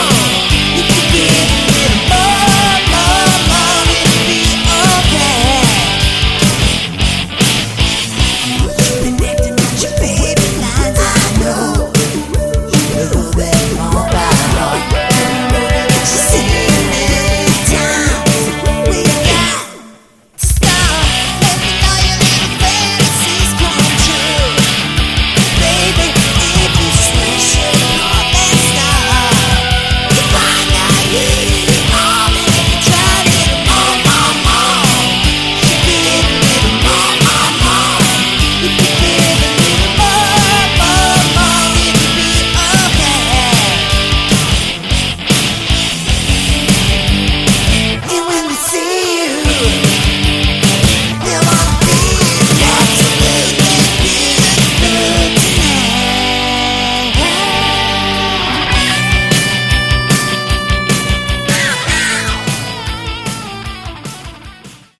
Category: Sleaze Glam